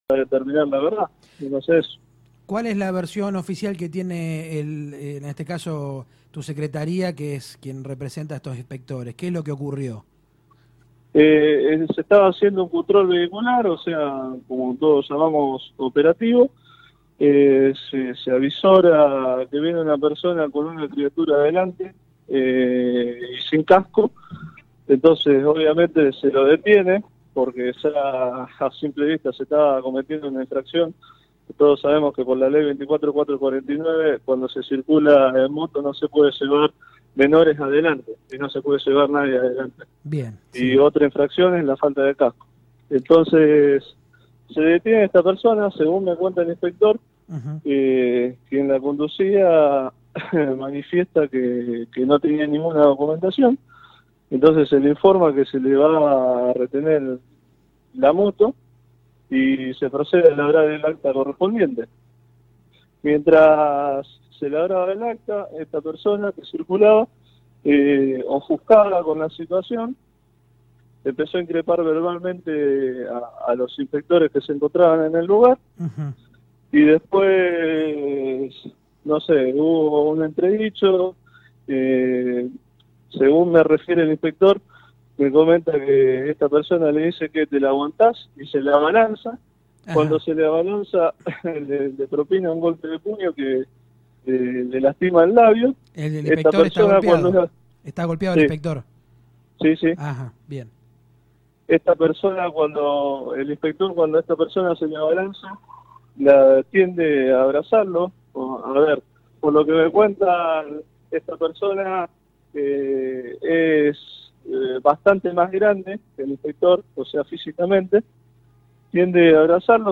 Así lo expresaba Leonardo Riera Subsecretario de tránsito de la Municipalidad en comunicación con Radio Verdad fm 99.5, escucha la nota aquí: